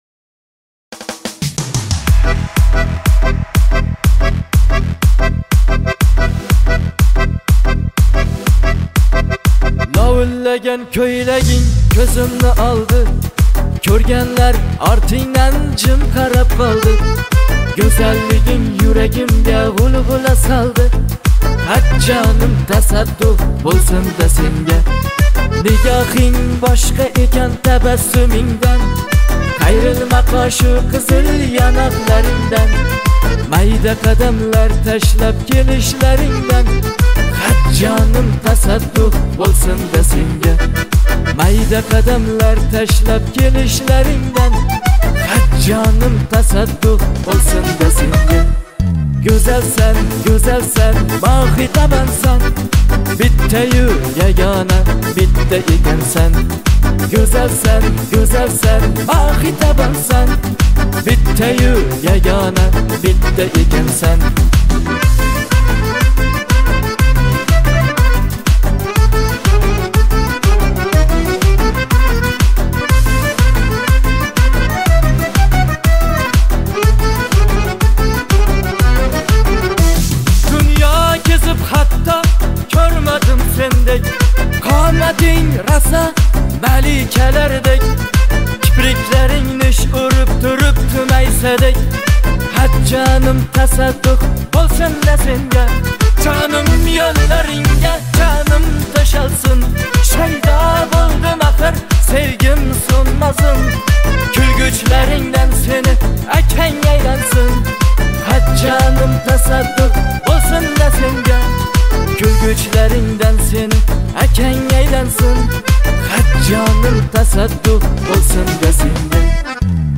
Узбекская песня